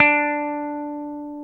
Index of /90_sSampleCDs/Roland L-CDX-01/GTR_Dan Electro/GTR_Dan-O 6 Str